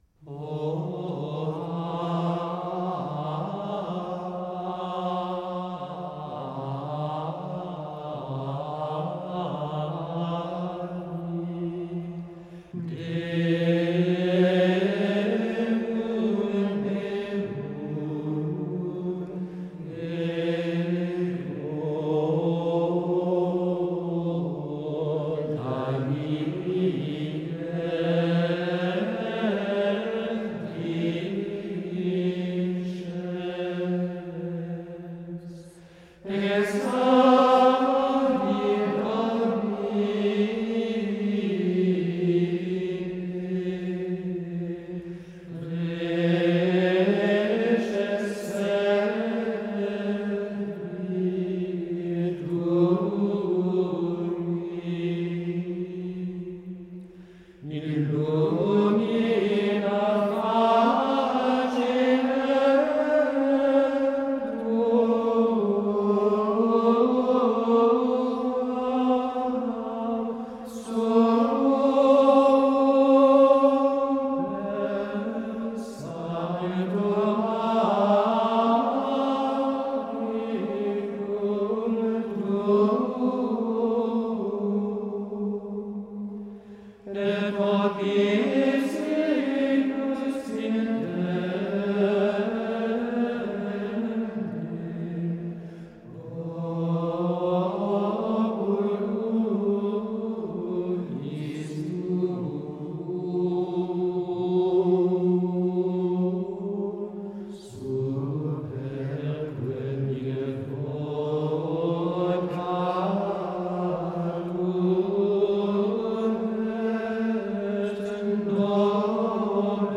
• Grégorien : introït paix
Mélodiquement, elle ne s’élève pas au-dessus du Sol.
Ce soulèvement de la mélodie sur le nom béni est plein de ferveur mais aussi d’espérance.
La deuxième phrase, assez courte, se poursuit et s’achève dans ce climat profond de douceur et d’espérance, oui l’espérance d’attirer le regard de Dieu et de toucher son cœur.
Le mouvement, toujours fervent, est néanmoins redevenu calme et intérieur.
un moine de Triors